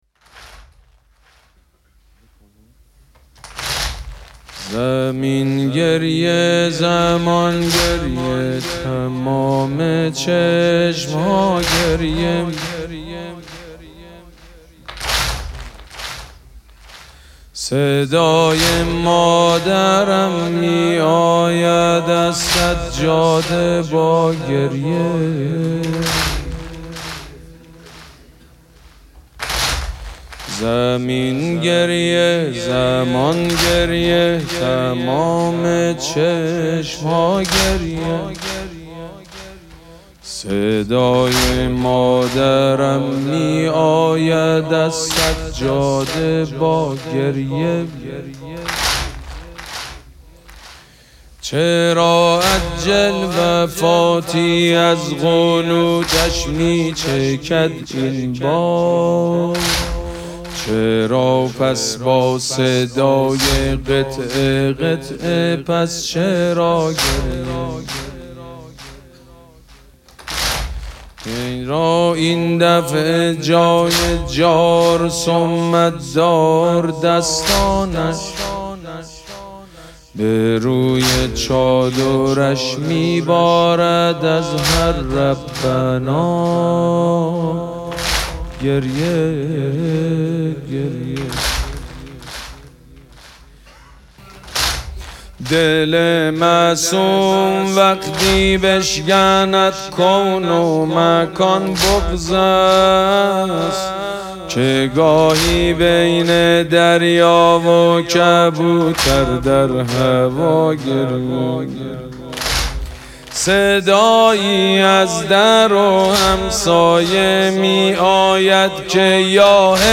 شب سوم مراسم عزاداری دهه دوم فاطمیه ۱۴۴۶
مداح